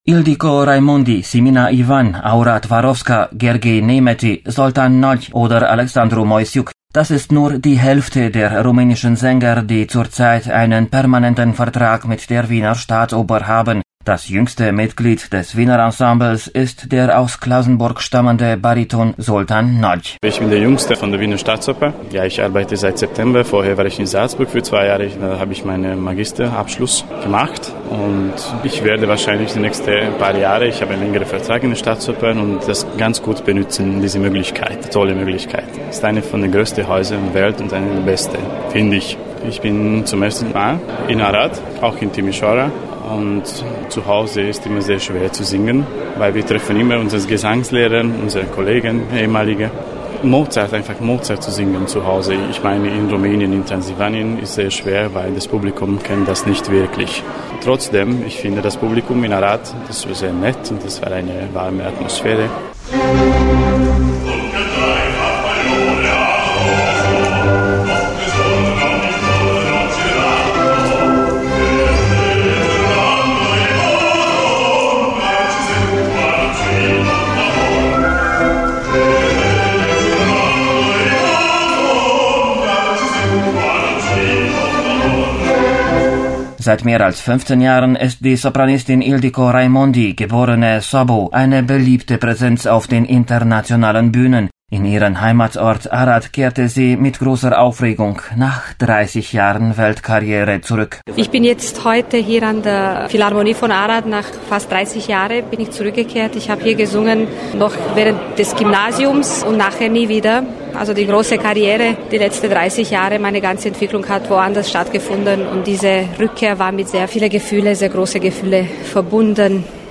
hat mit ihnen nach dem Konzert in Arad gesprochen.